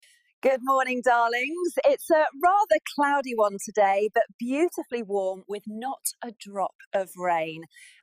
Weather Gril Says Its Cloudy Sound Effects Free Download